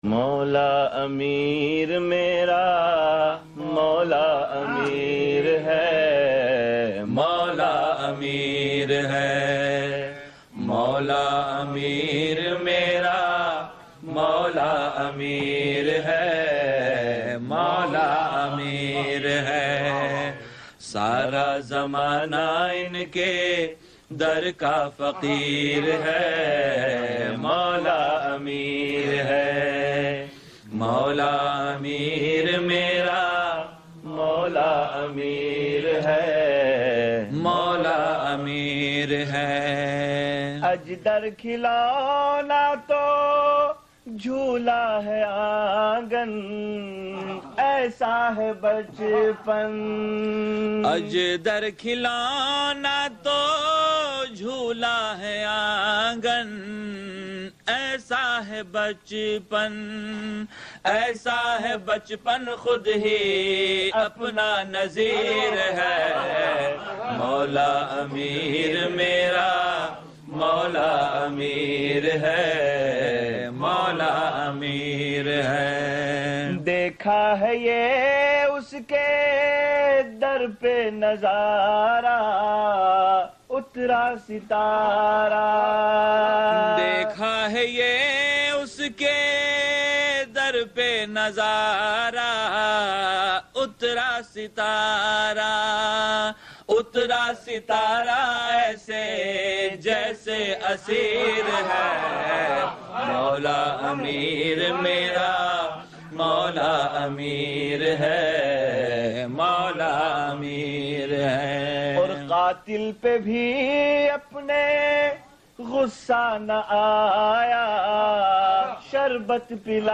Non Saff Classic Munajaats